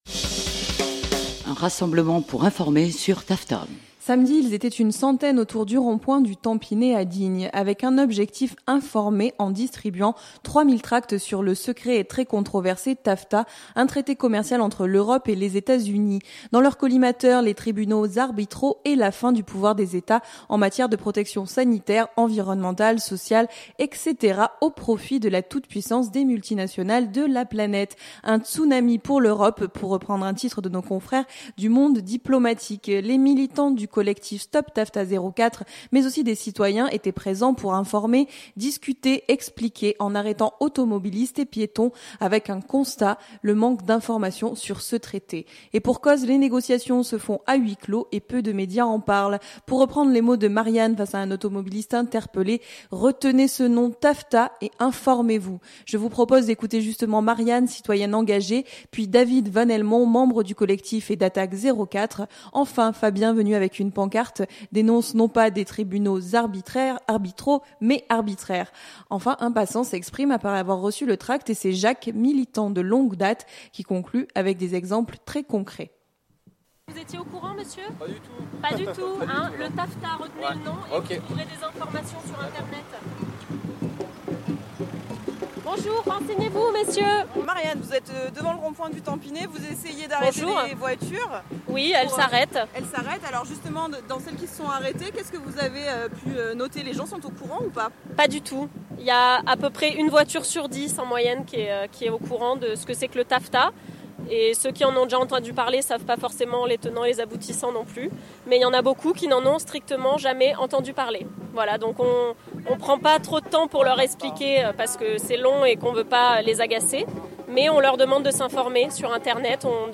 Samedi ils étaient une centaine autour du rond-point du Tampinet à Digne. Avec un objectif : informer en distribuant 3 000 tracts sur le secret et très controversé Tafta, un traité commercial entre l’Europe et les Etats-Unis.
» L’ambiance était assurée grâce à une batucada venue en renfort, et le rassemblement s'est poursuivi sur le boulevard Gassendi puis au marché avec quelques prises de parole.